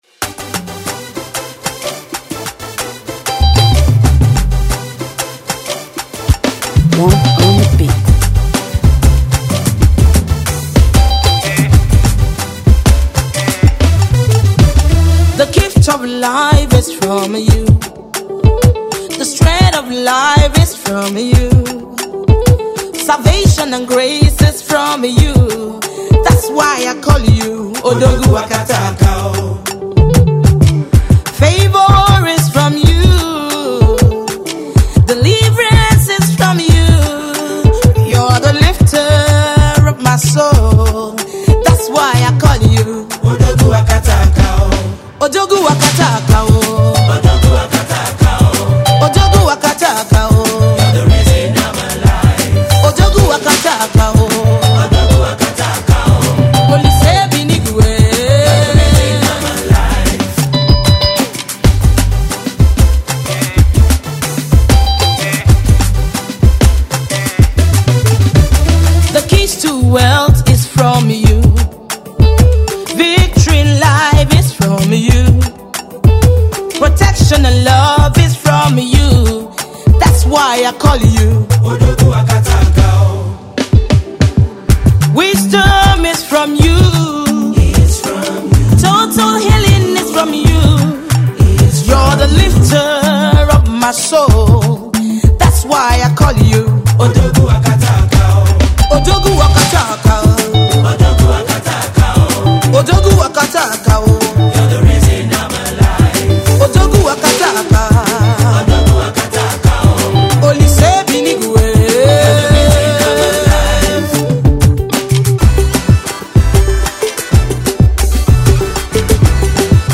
Nigerian talented contemporary gospel